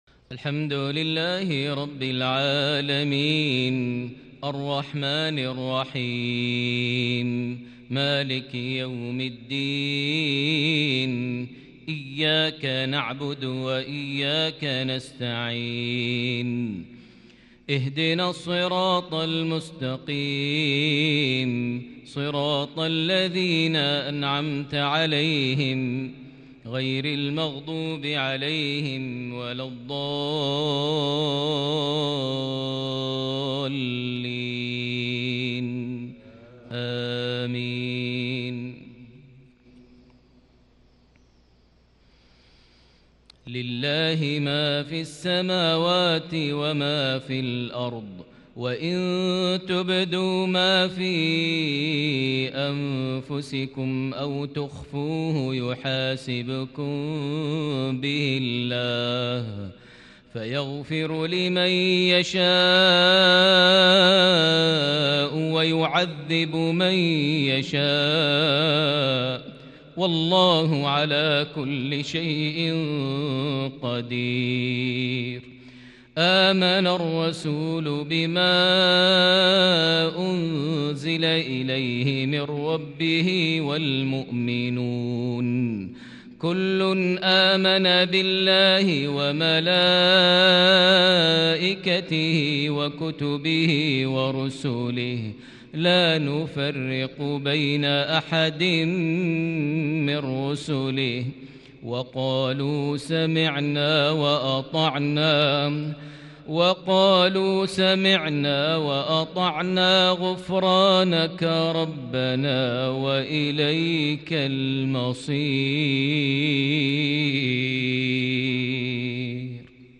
صلاة المغرب ١٧ شعبان ١٤٤١هـ خواتيم سورة البقرة > 1441 هـ > الفروض - تلاوات ماهر المعيقلي